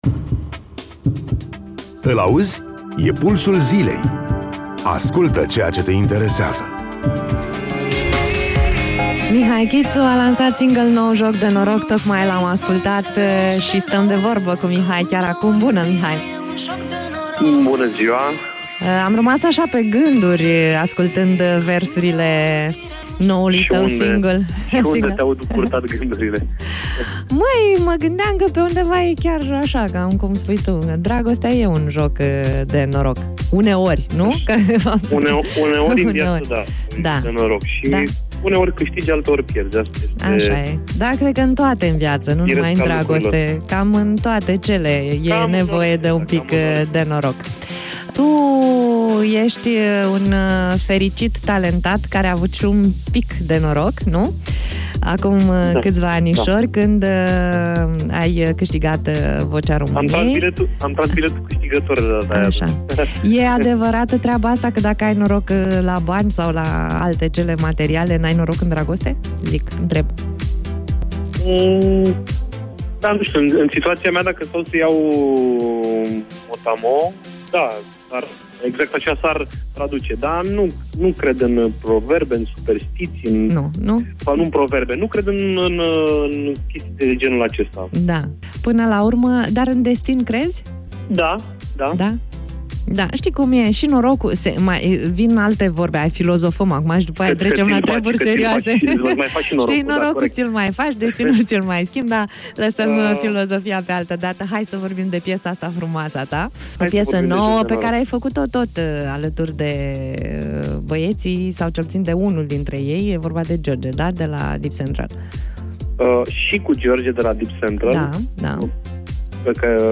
în direct la Radio Iaşi – Pulsul Zilei